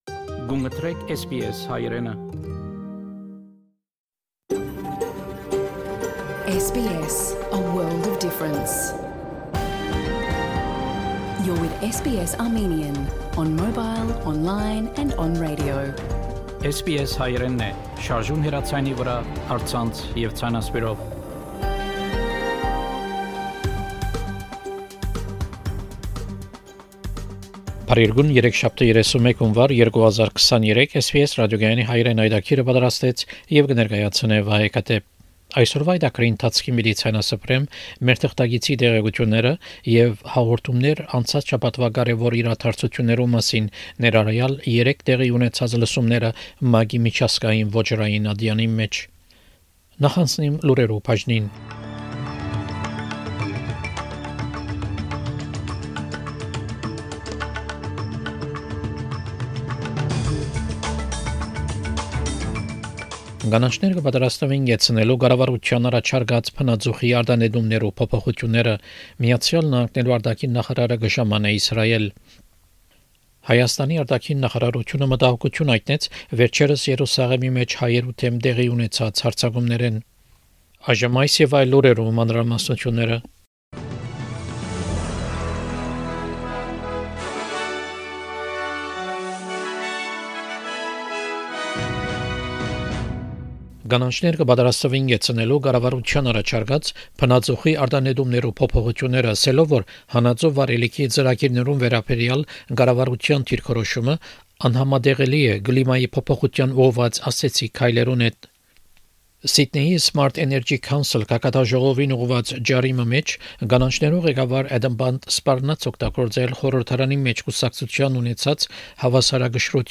SBS Հայերէնի լուրերը քաղուած 31 Յունուար 2023 յայտագրէն: